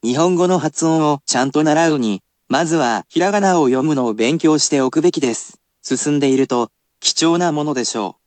[basic polite speech]